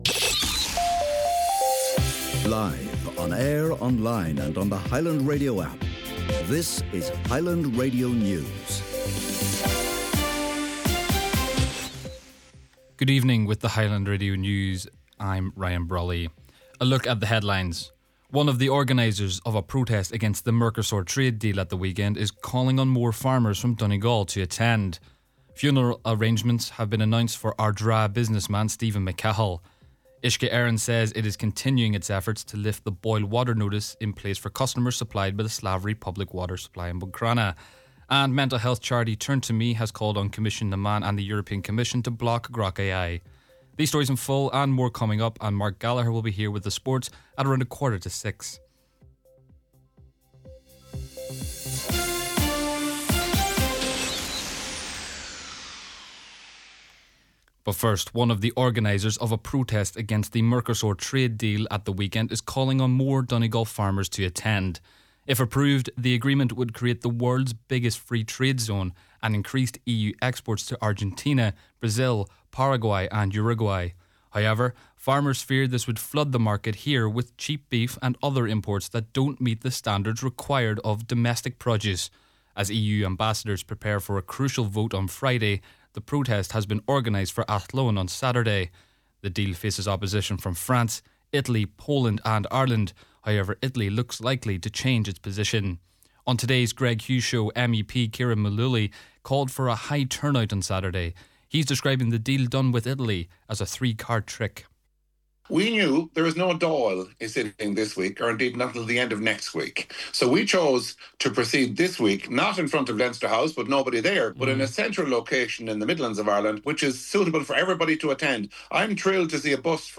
Main Evening News, Sport & Obituary Notices – Wednesday January 7th